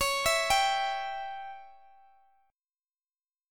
Listen to Dbdim strummed